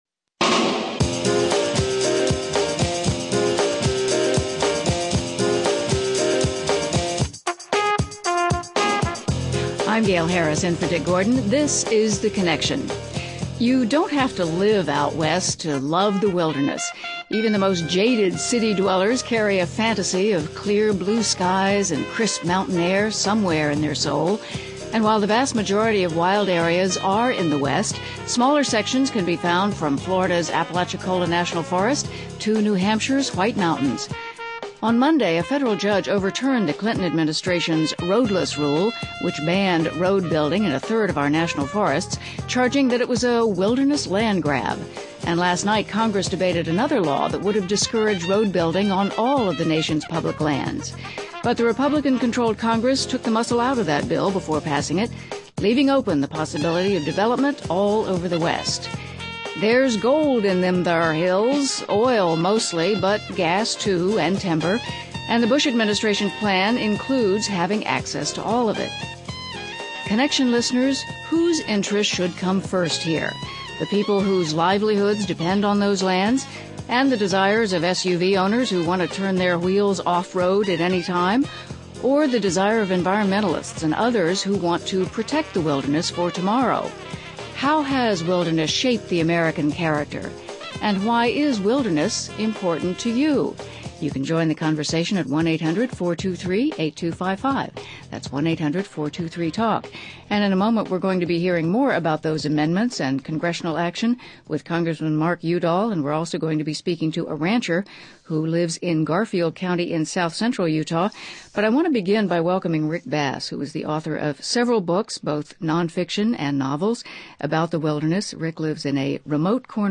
Guests: Rick Bass, nature writer and novelist, Congressman Mark Udall, Democrat from Colorado